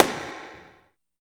78 SNARE  -L.wav